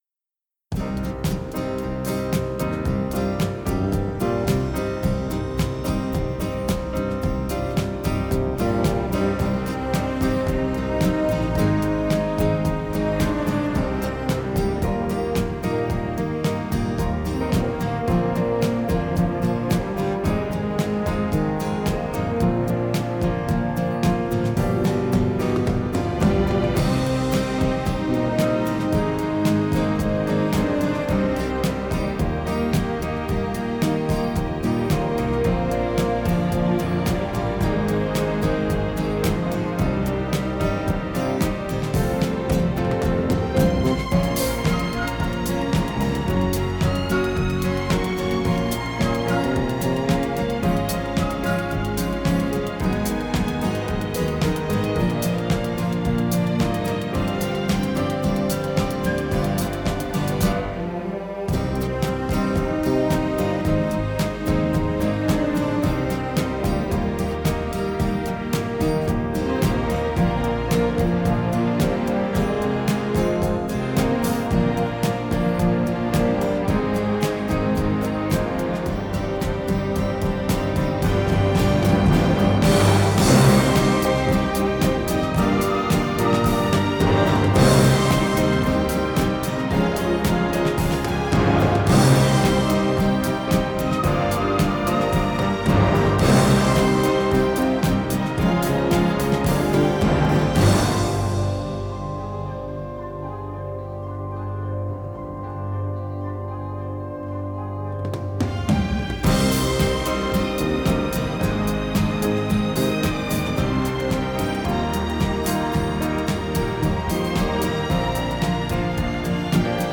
Pop Orchestra Version